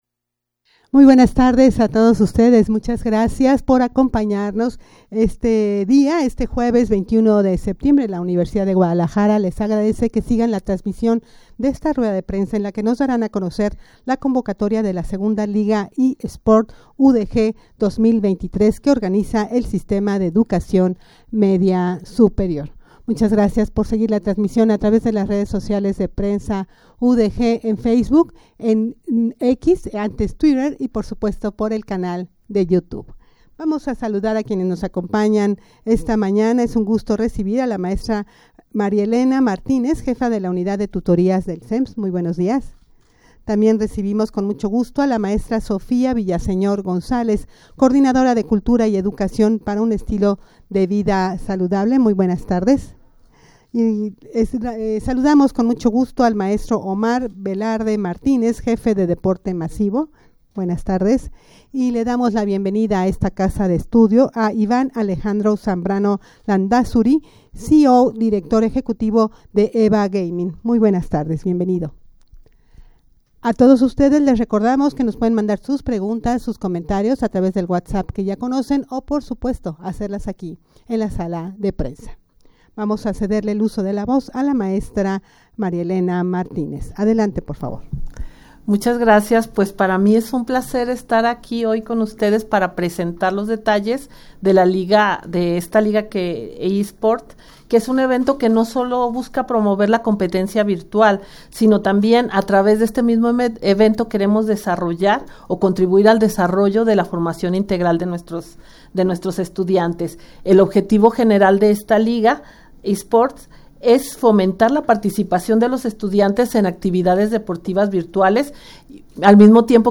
rueda-de-prensa-para-dar-a-conocer-la-convocatoria-de-la-segunda-liga-e-sport-udg-2023.mp3